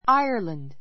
Ireland áiə r lənd ア イアらン ド 固有名詞 ❶ アイルランド島 ⦣ Great Britain 西方の島で, 北部は英国の一部, 南部は「アイルランド共和国」. the Emerald Isle ア イ る （エメラルド島）の別名がある. ❷ アイルランド共和国 ⦣ 正式名 the Republic of Ireland .